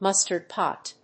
アクセントmústard pòt